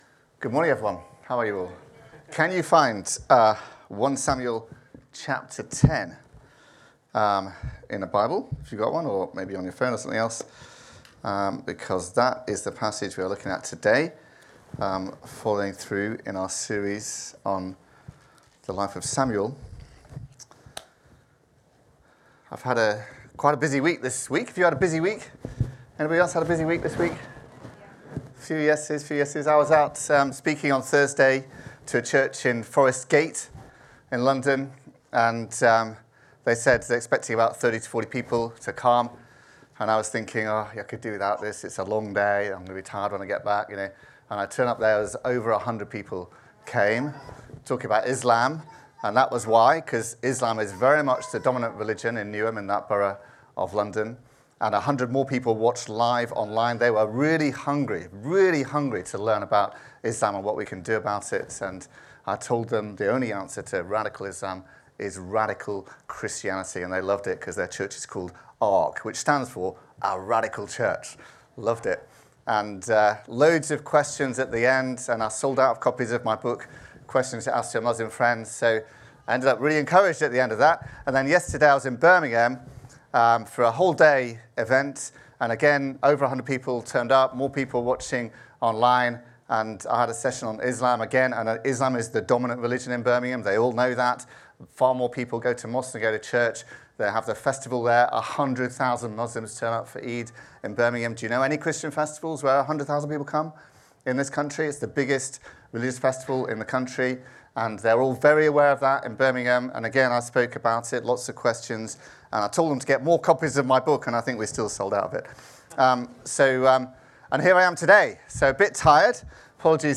Download Anointing and Confirmation | Sermons at Trinity Church